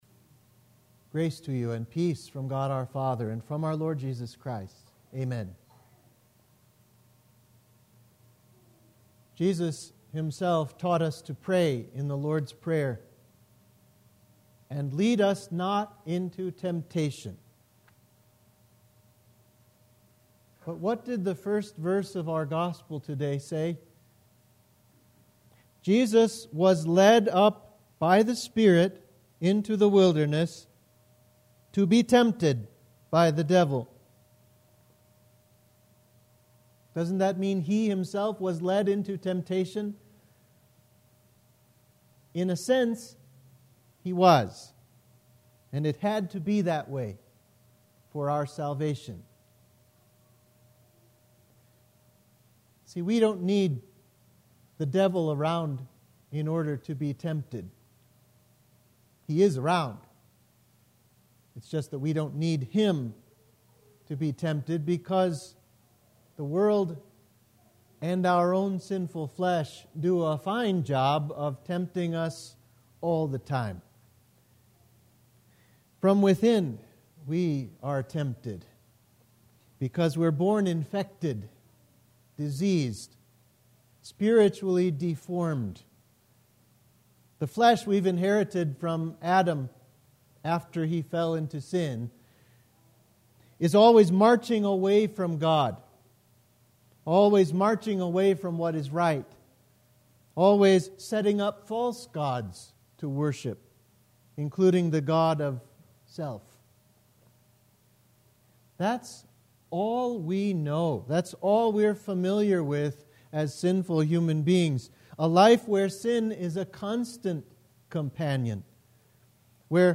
Sermon for Invocavit – Lent 1